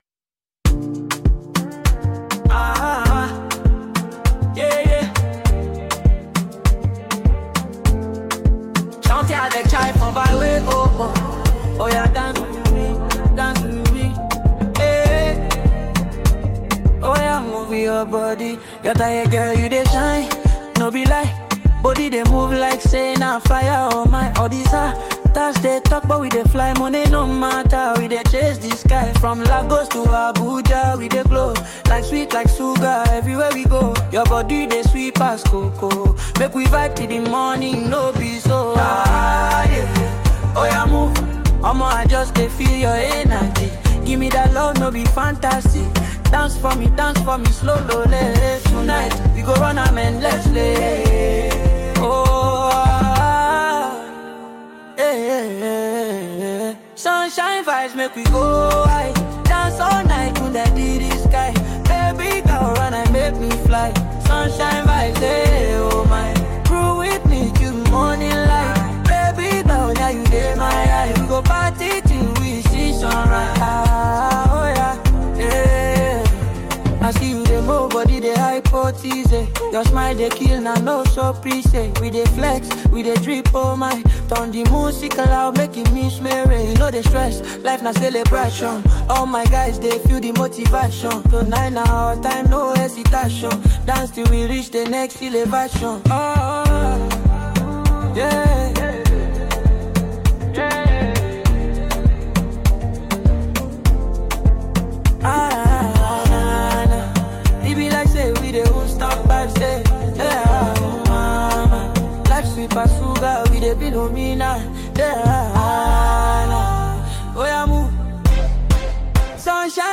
Afrosounds/Afrobeats